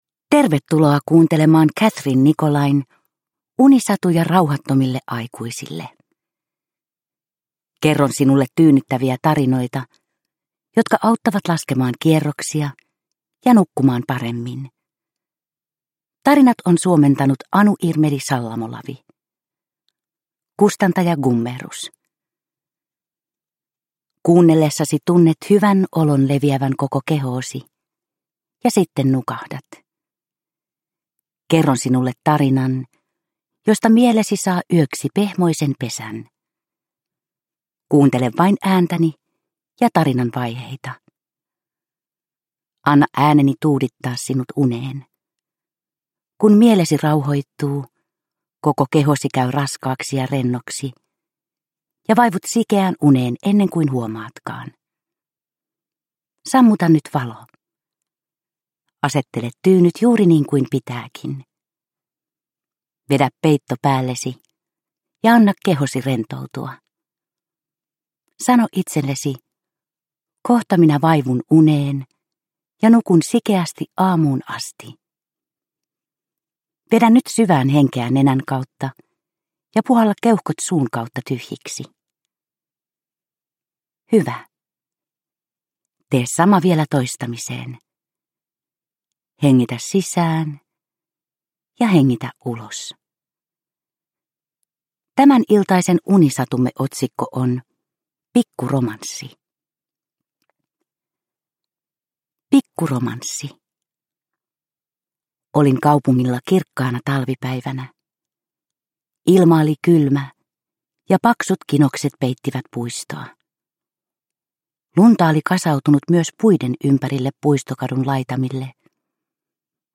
Unisatuja rauhattomille aikuisille 18 - Pikku romanssi – Ljudbok – Laddas ner